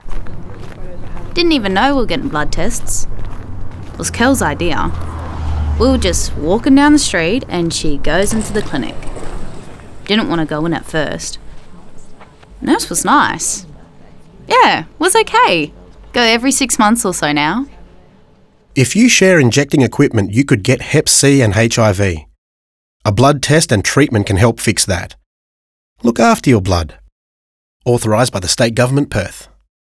Male-30-second-radio.wav